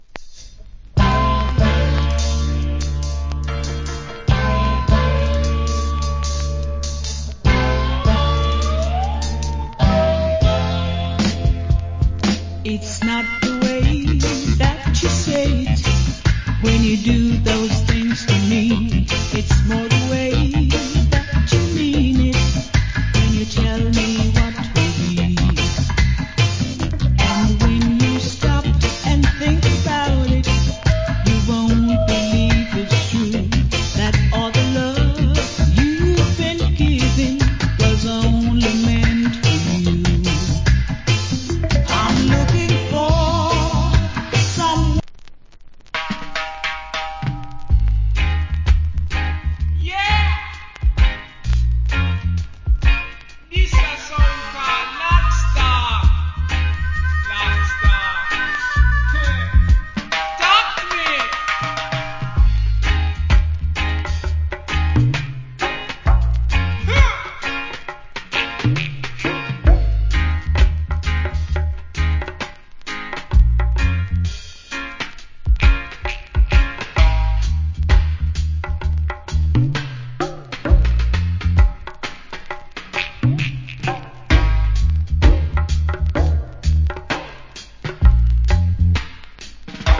Good Female Reggae Vocal.